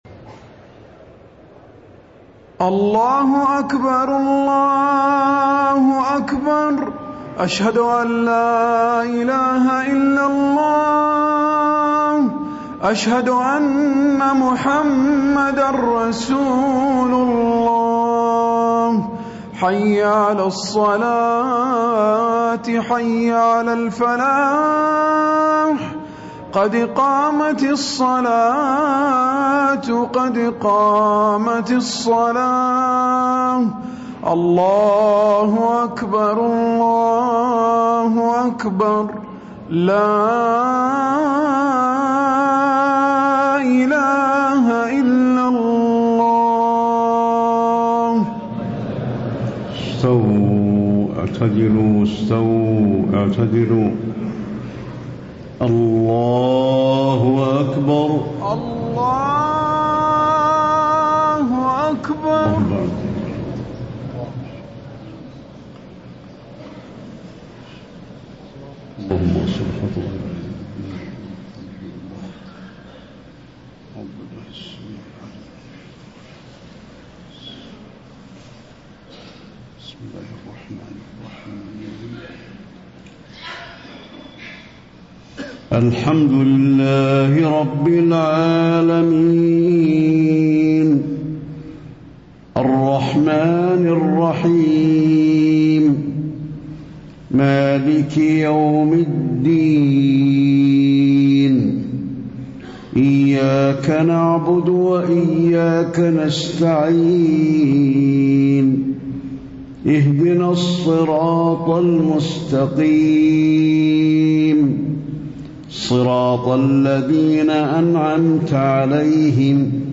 صلاة المغرب 2-6-1435 سورتي البلد و العاديات > 1435 🕌 > الفروض - تلاوات الحرمين